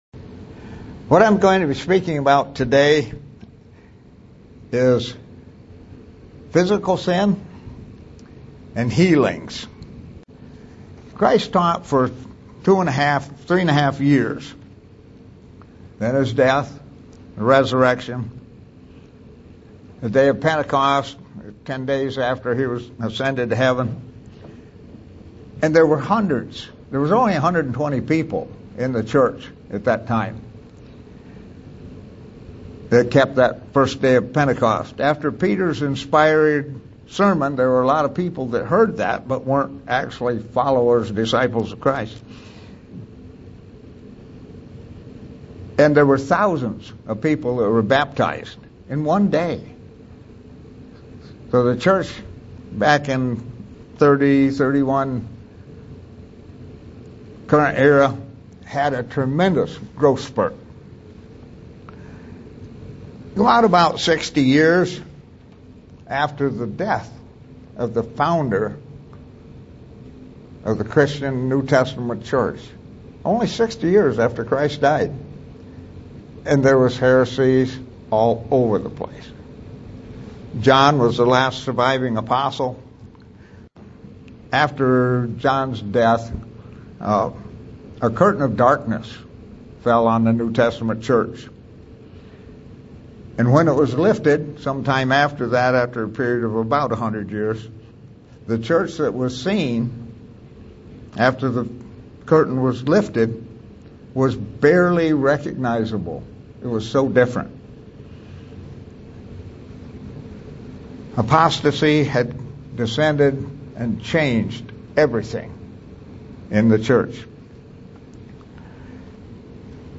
SEE VIDEO BELOW UCG Sermon Studying the bible?